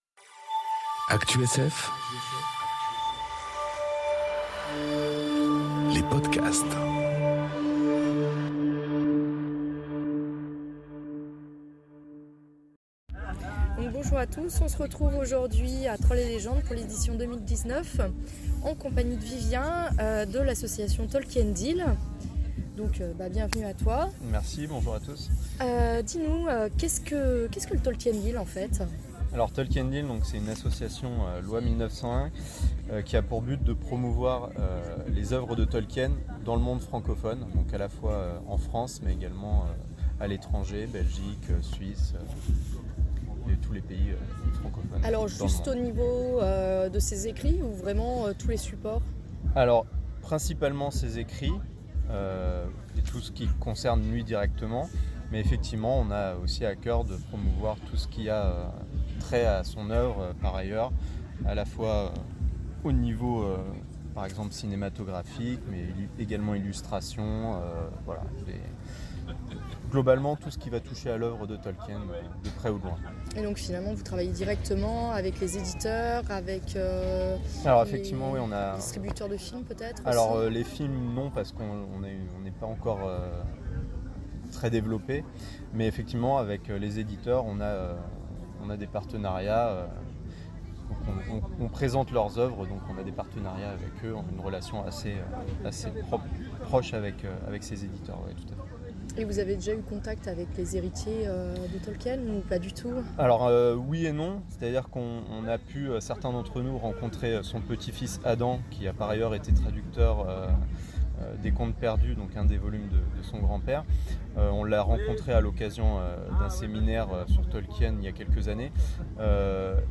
Trolls et Légendes 2019 - Rencontre avec Graham Masterton